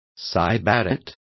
Complete with pronunciation of the translation of sybarite.